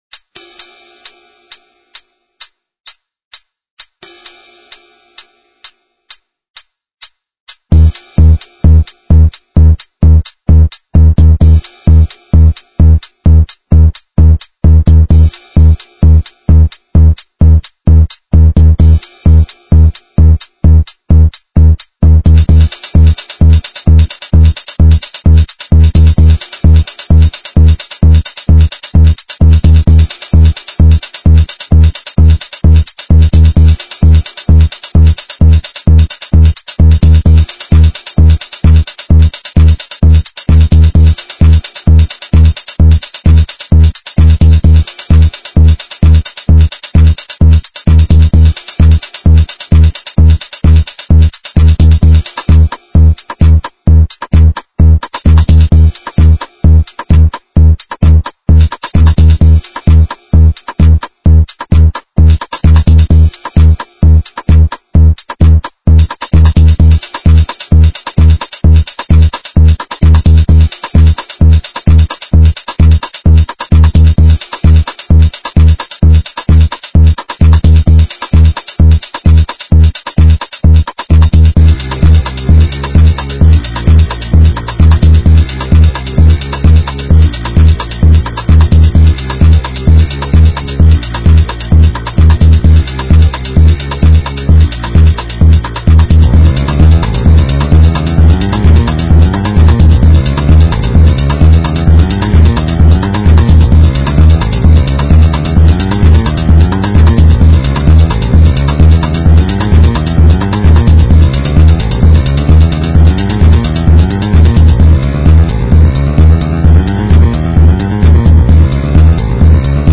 surrogate/post techno